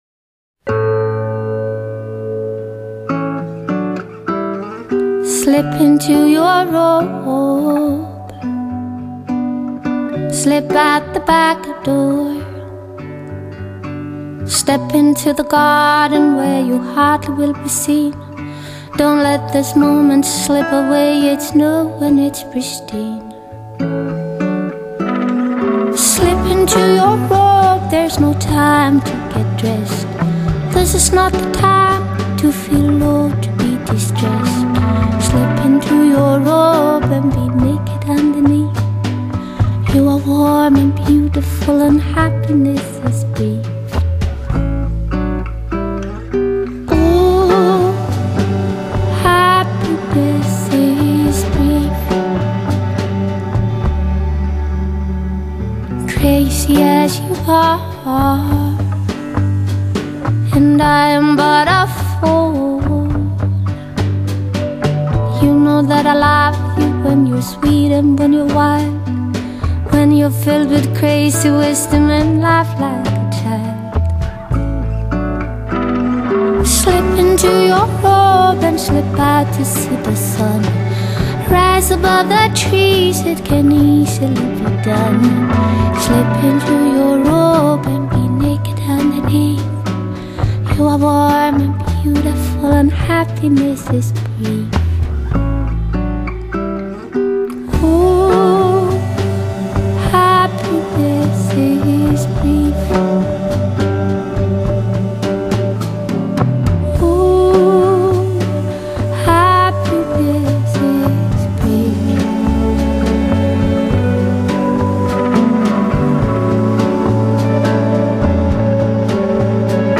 Genre: Jazz, Folk, Singer-Songwriter
她的嗓音很baby-voice，细腻中带有一点淘气，完全没有黑人女歌手那种底气十足的浑厚嗓音。
她的声音或许不像小孩子那般纯净，却很甜，也许有人不喜欢这样的甜味，怕腻，但这确是让人听了舒服开心的声音。